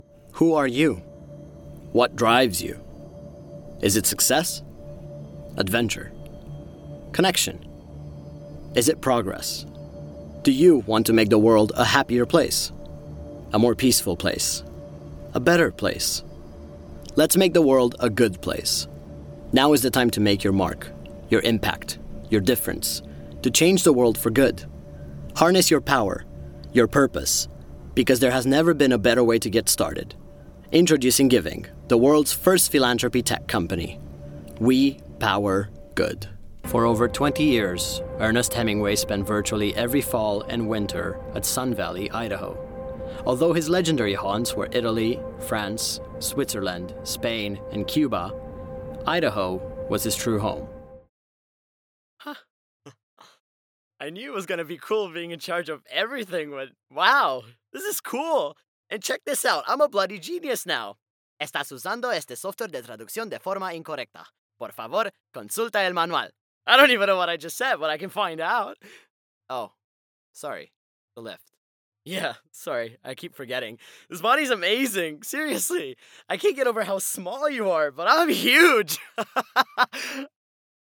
Eastern European, Romanian, Male, Home Studio, 20s-30s